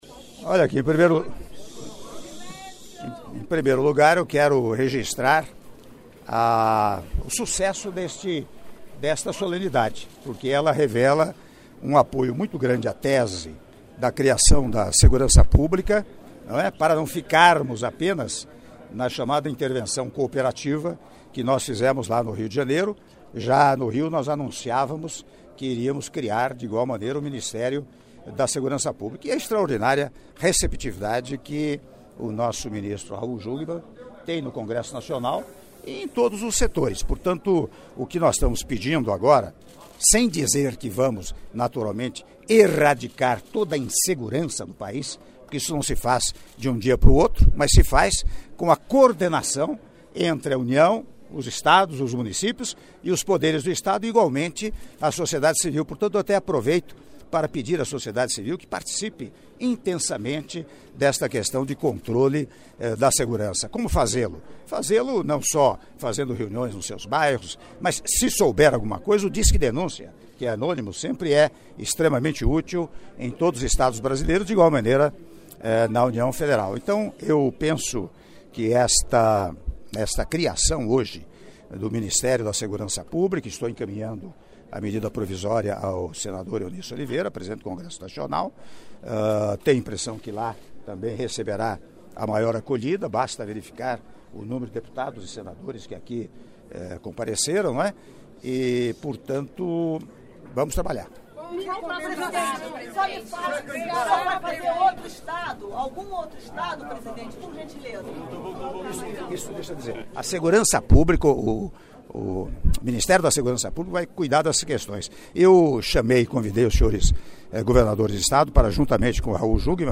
Áudio da entrevista concedida pelo Presidente da República, Michel Temer, após Cerimônia de Posse do Ministro de Estado Extraordinário da Segurança Pública, Raul Jungmann - Brasília-DF - (02min40s)